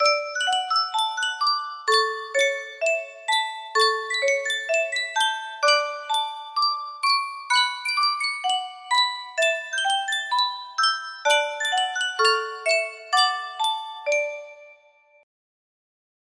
Sankyo Music Box - I'm Just Wild About Harry TAT music box melody
Full range 60